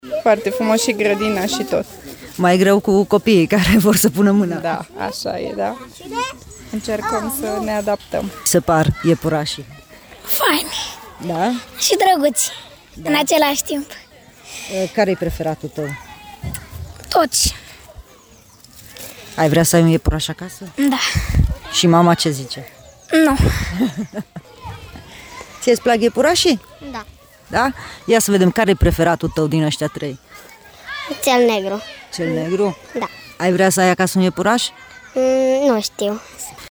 Primii vizitatori ai iepuraşilor au fost încântaţi de moment: